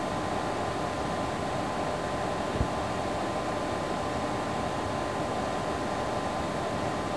ファンノイズ比較